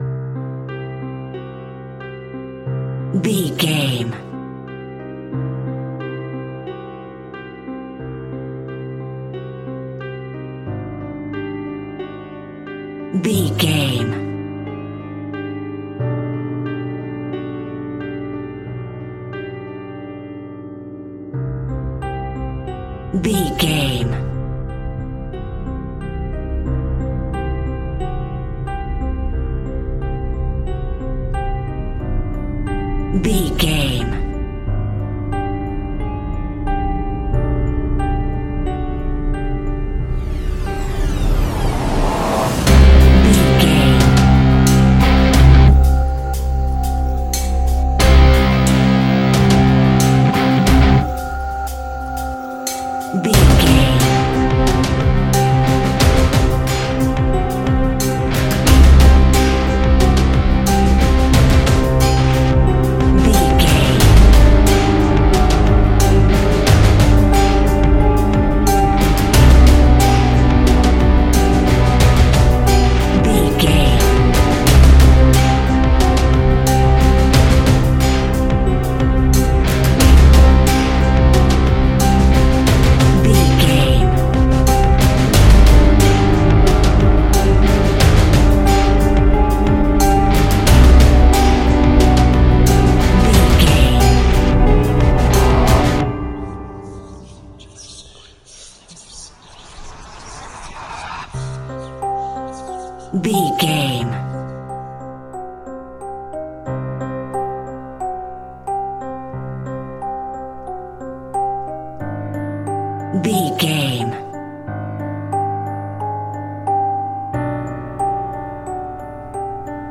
Category: Music